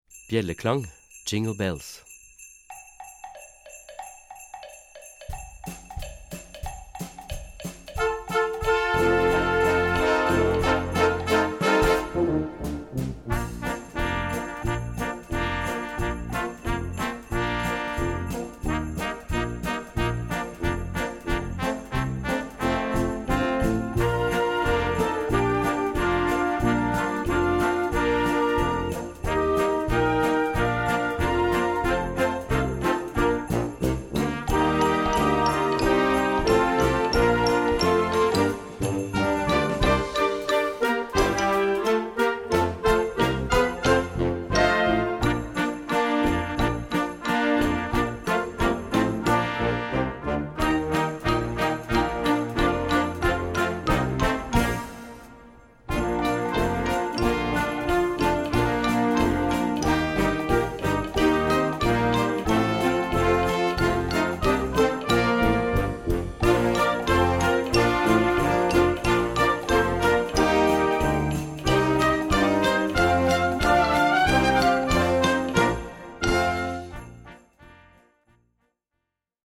A Besetzung: Blasorchester Tonprobe